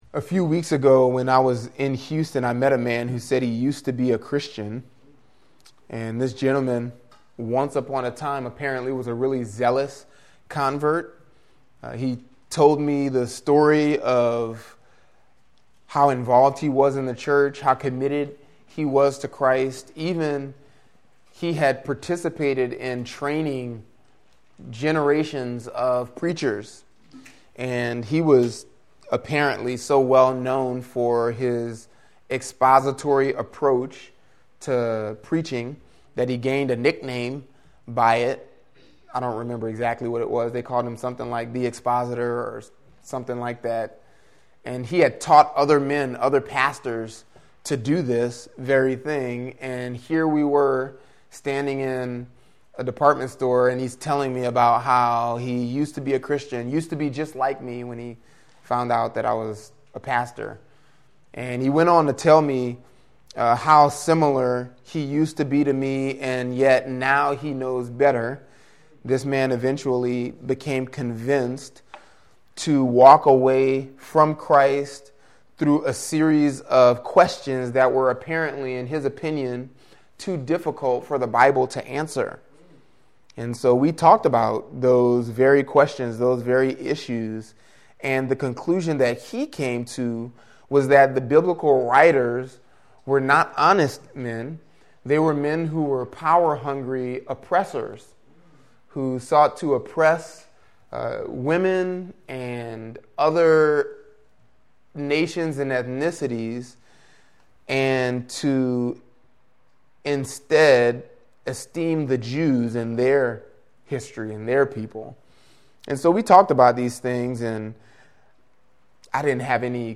Podcast (gbc-nola-sermons): Play in new window | Download